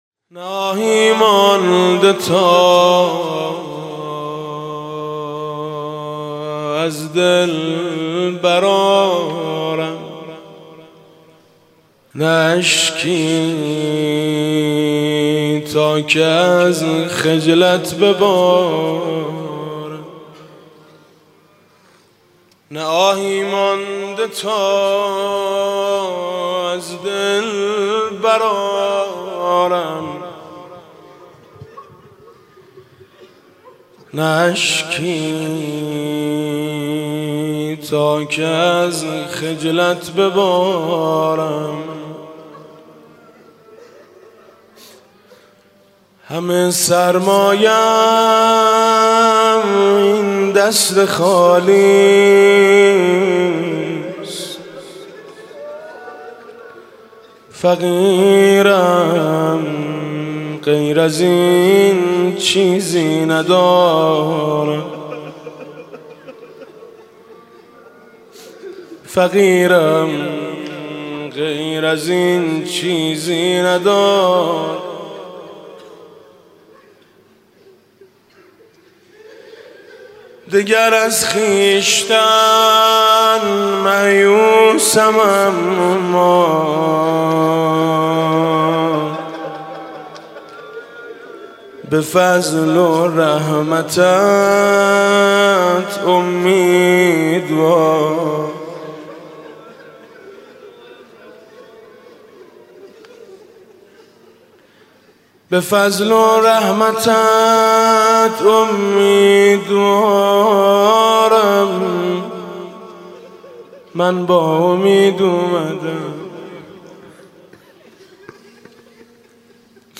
شب اول ماه مبارک رمضان
مناجات با خدا: همه سرمایه ام این دست خالی است
مناجات و روضه: مرغی که زمین خورد پرش را نزنید